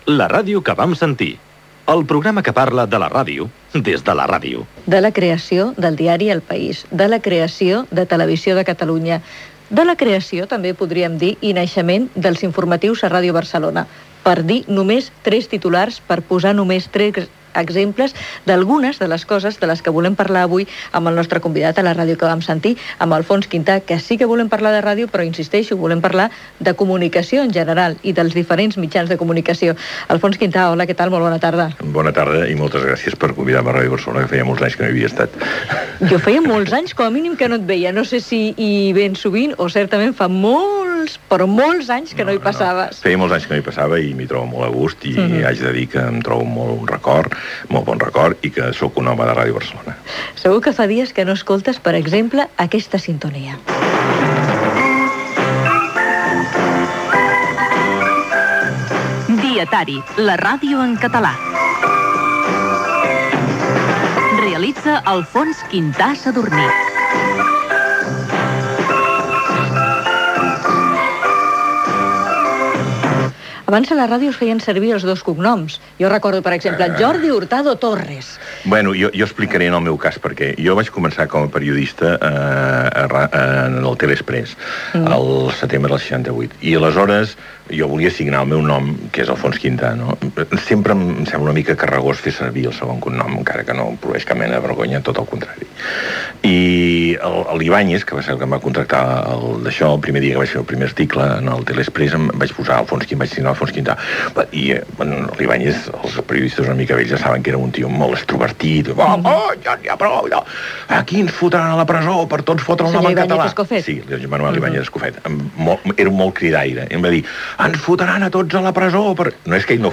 Indicatiu del programa. Entrevista a Alfons Quintà i Sadurní, sobre la seva trajectòria al món de la comunicació i parla del programa "Dietari" a Ràdio Barcelona